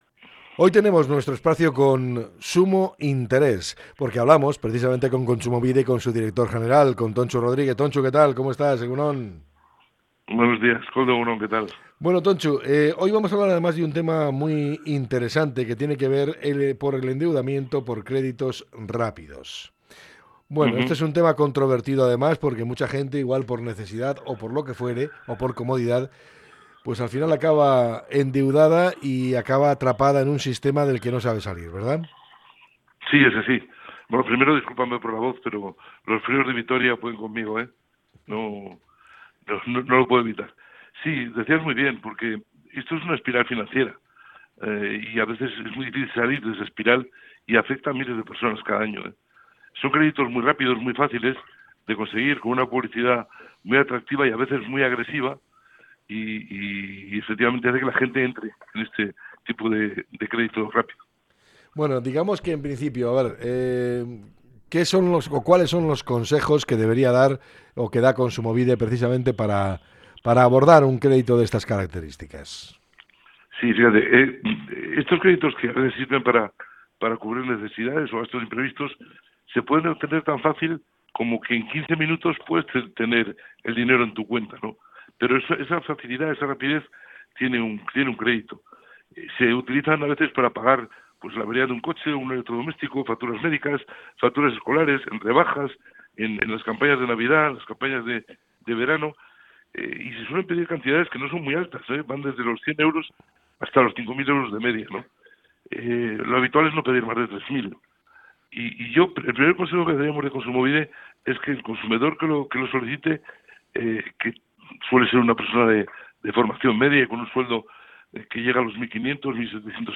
Tontxu Rodríguez, director general de Kontsumobide, alerta de sus peligros y aporta consejos para abordar este tipo de créditos
Hablamos con Tontxu Rodríguez, director general de Kontsumobide, para conocer algunos consejos para abordar un crédito de estas características.